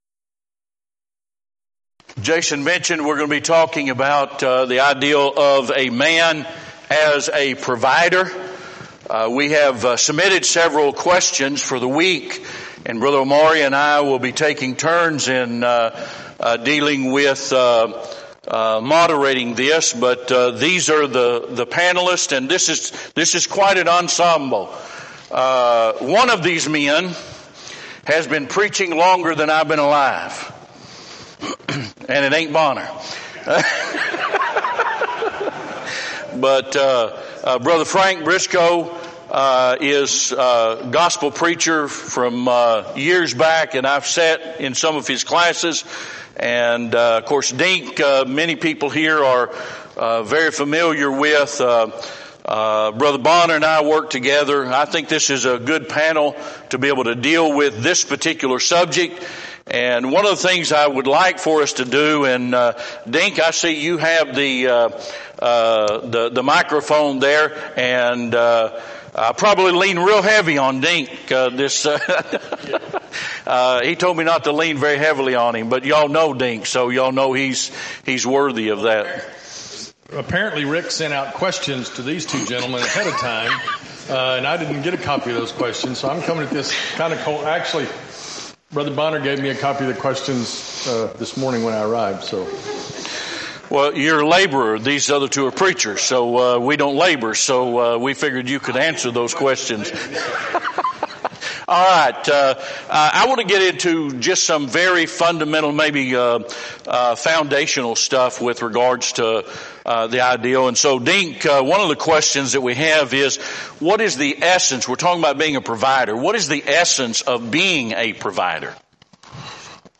Event: 6th Annual BCS Men's Development Conference
lecture